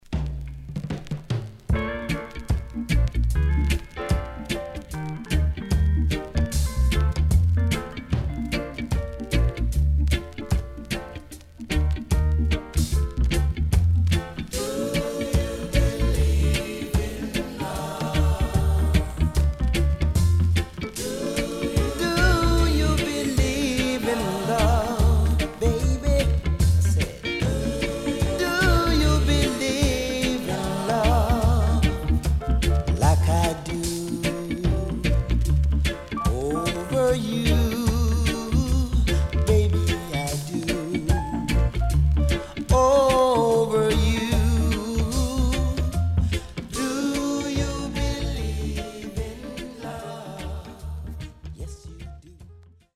CONDITION SIDE A:VG(OK)
74年両面極上Sweet Vocal
SIDE A:所々チリノイズがあり、少しプチノイズ入ります。